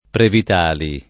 Previtali [ previt # li ] cogn.